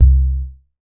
MoogAlone B.WAV